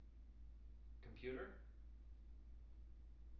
wake-word
tng-computer-339.wav